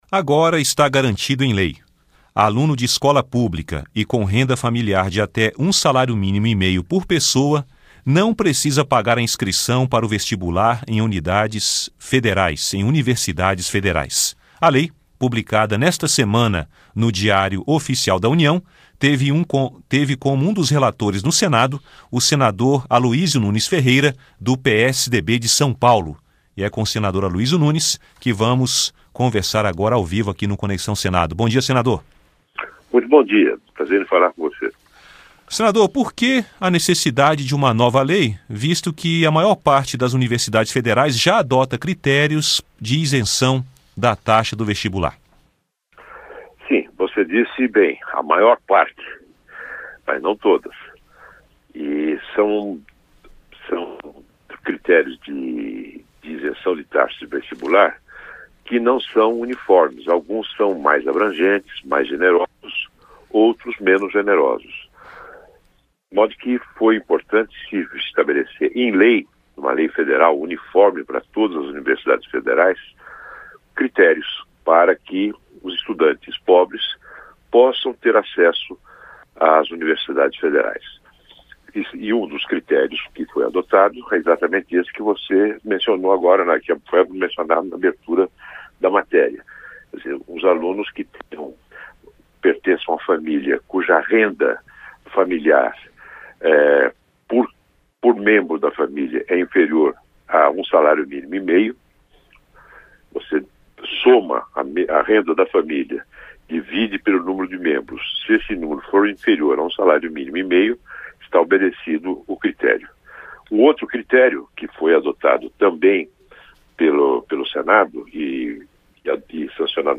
Entrevista com o senador Aloysio Nunes (PSDB-SP).